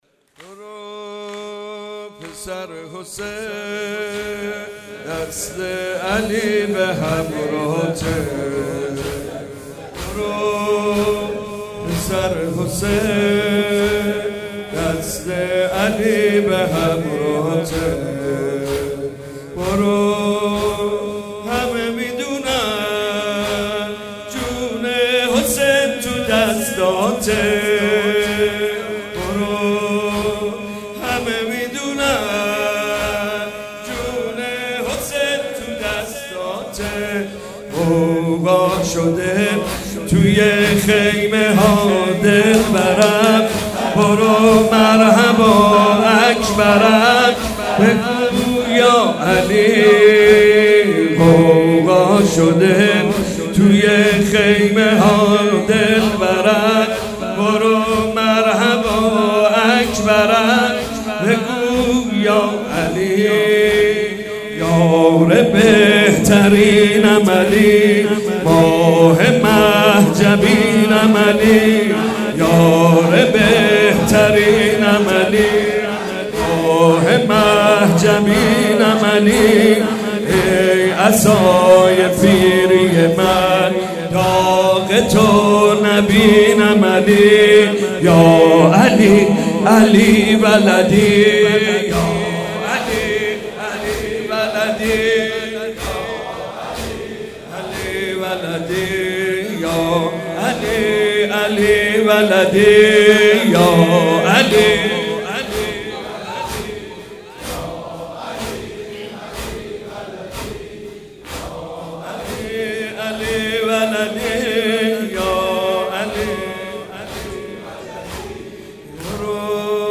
شب هشتم محرم95/هیئت مکتب الزهرا(س)
زمینه/برو پسر حسین(ع)